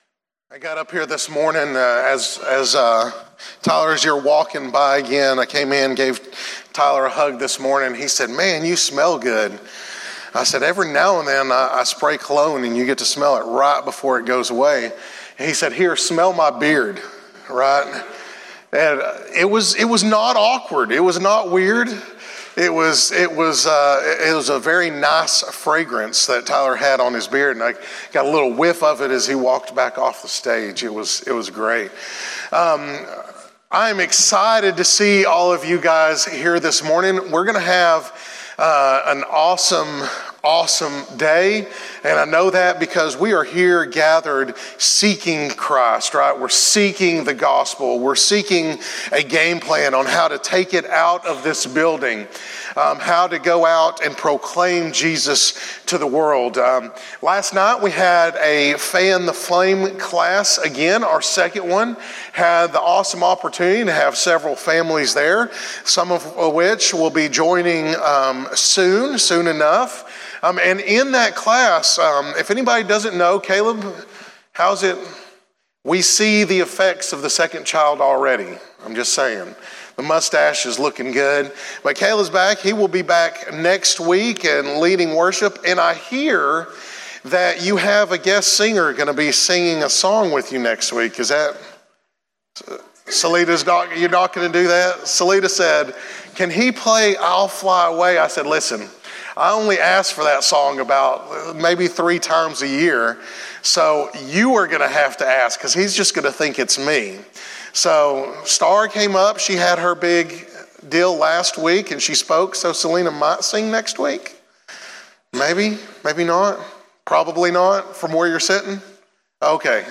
Recent Sermons
sermon.cfm